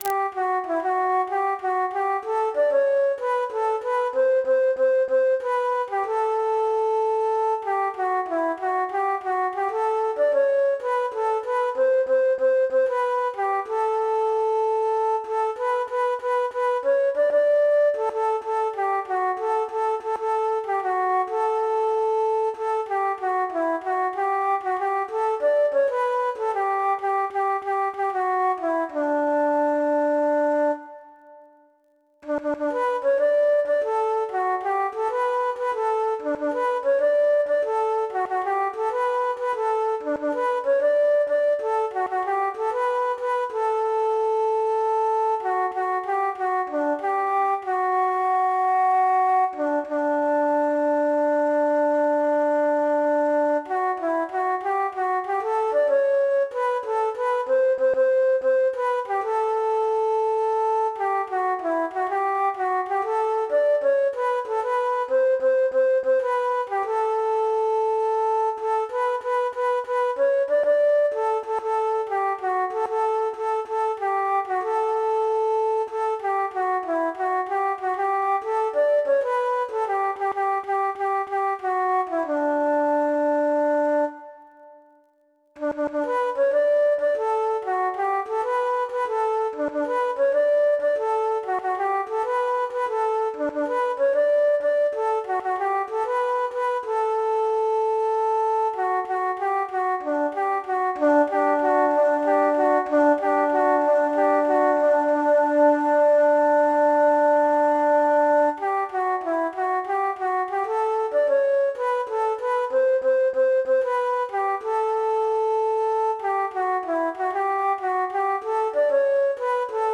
MIDI-type recording